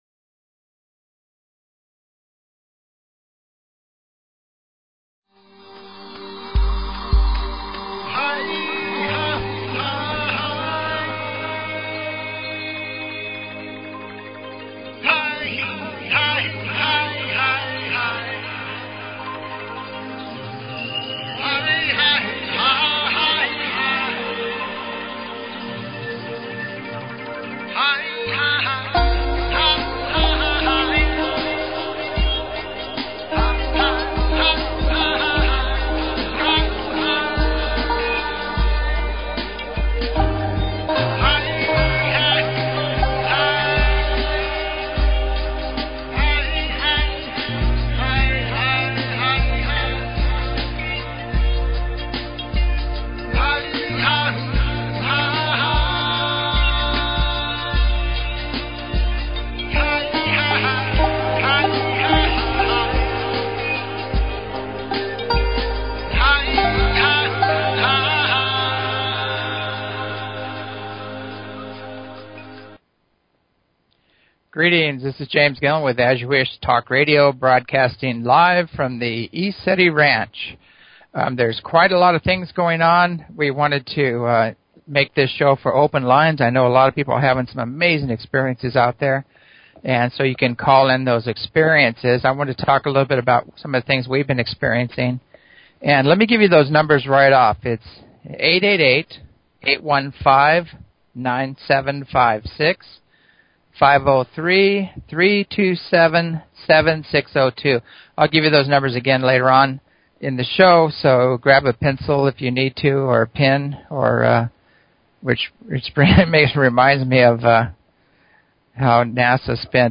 Talk Show Episode, Audio Podcast, As_You_Wish_Talk_Radio and Courtesy of BBS Radio on , show guests , about , categorized as
Call Ins, planetary alignmenst getting through the energy shifts